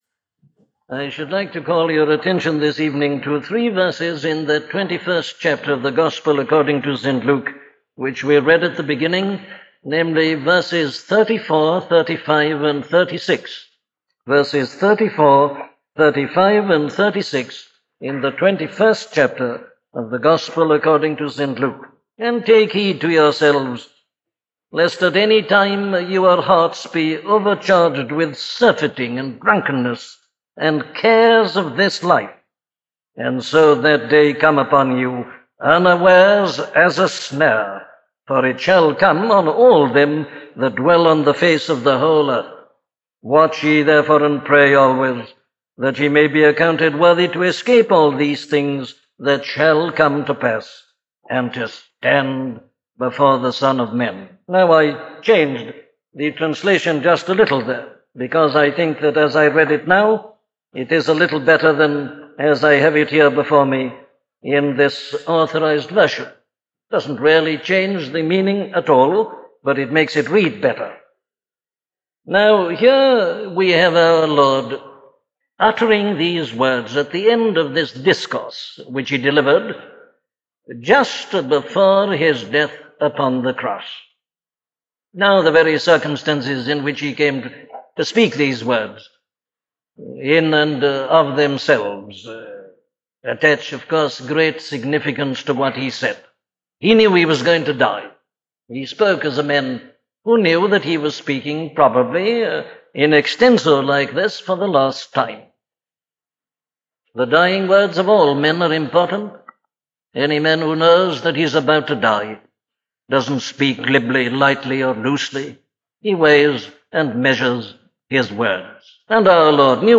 The End of the World - a sermon from Dr. Martyn Lloyd Jones